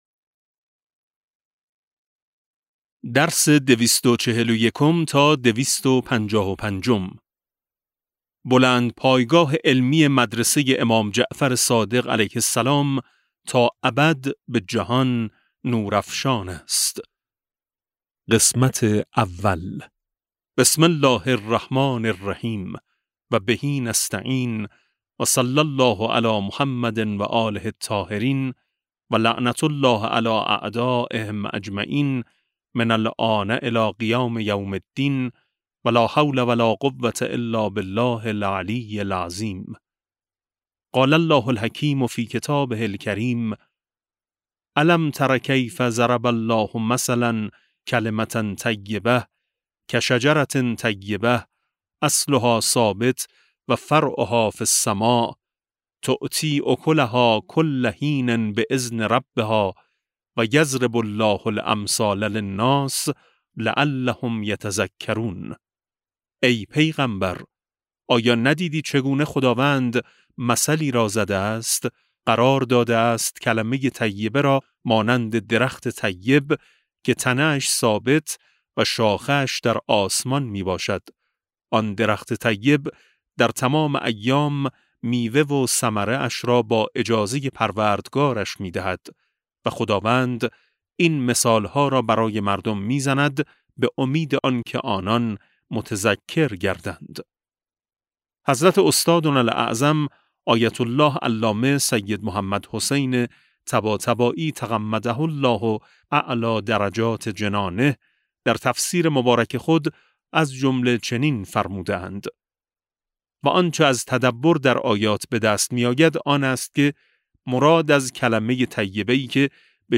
کتاب صوتی امام شناسی ج 16 و17 - جلسه12